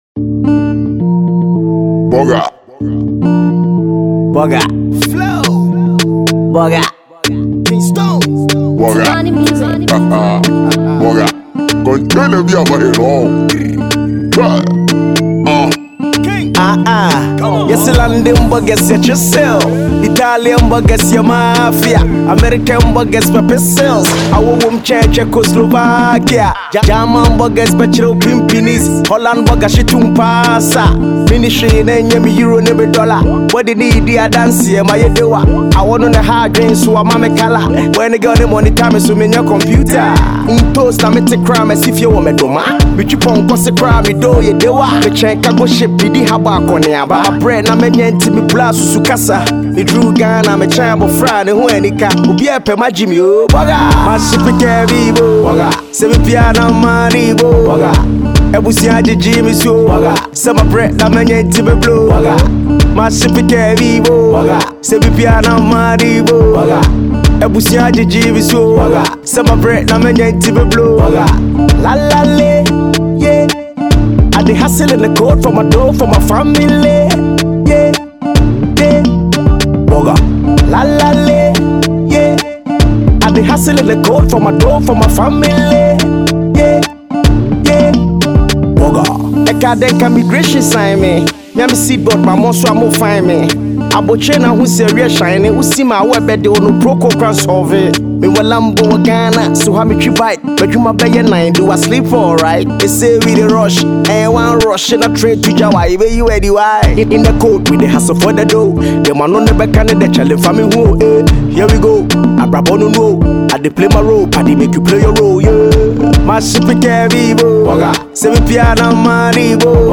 Kumasi rapper